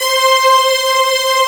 47 PAD    -L.wav